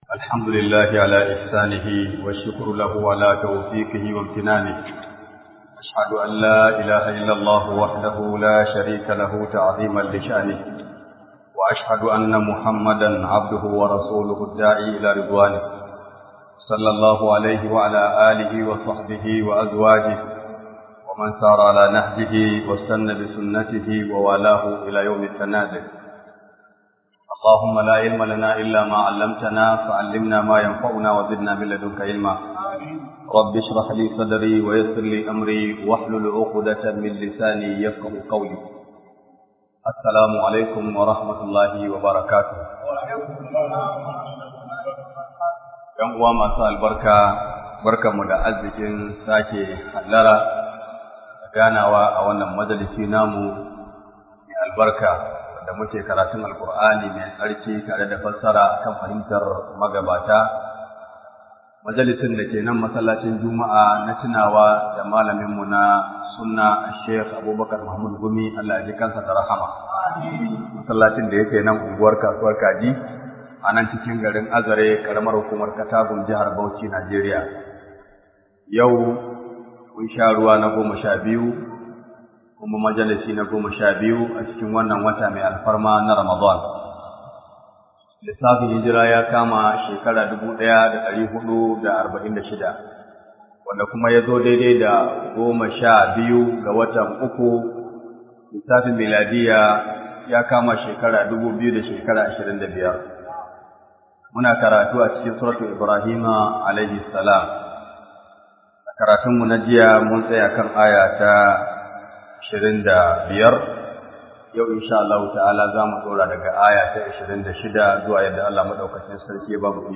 012 RAMADAN TAFSEER 1446